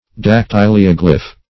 \dac*tyl"i*o*glyph\ (d[a^]k*t[i^]l"[i^]*[-o]*gl[i^]f)
dactylioglyph.mp3